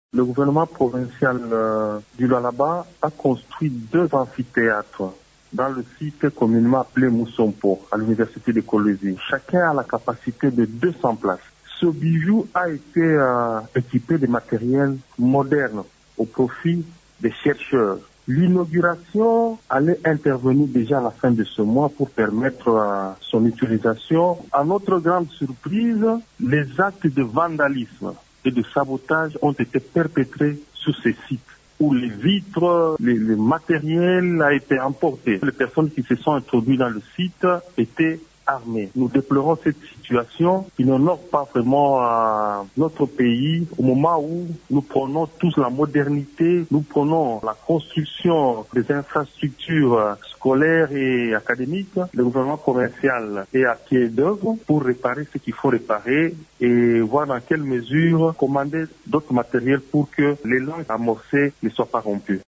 Vous pouvez écouter Jules Kabwit dans cet extrait sonore.